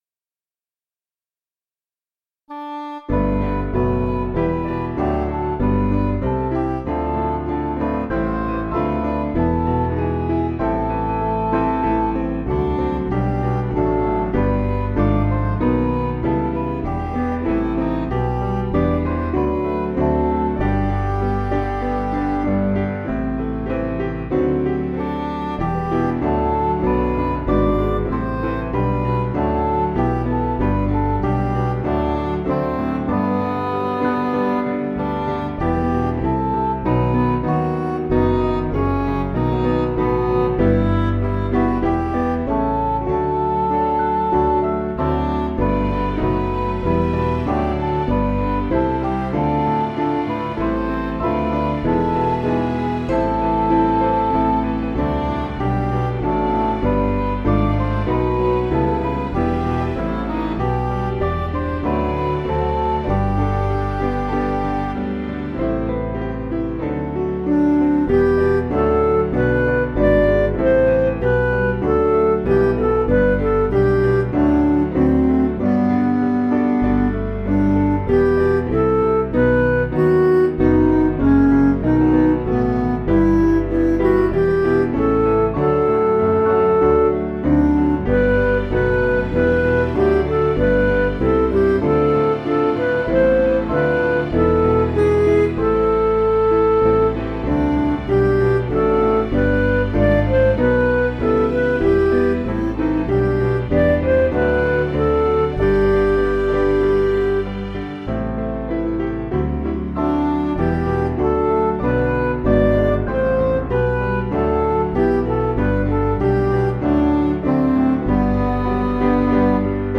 Piano & Instrumental
(CM)   4/G